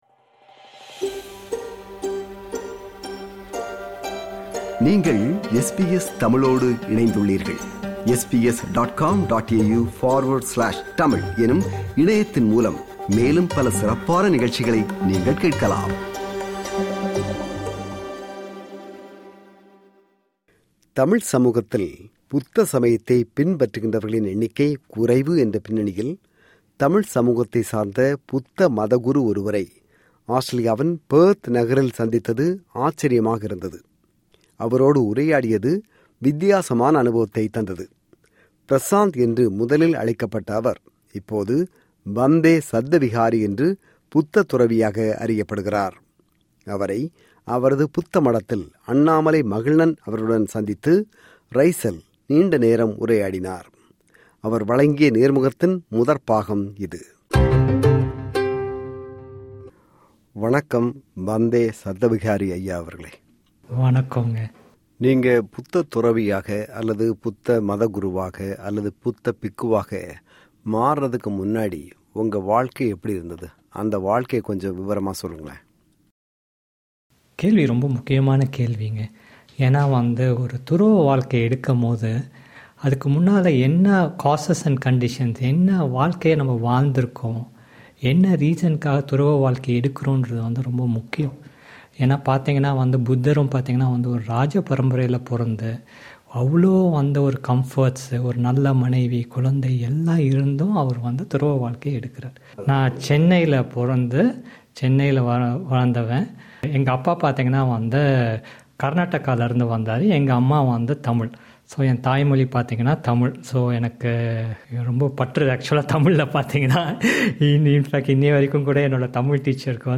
அவர் வழங்கிய நேர்முகத்தின் முதற்பாகம்.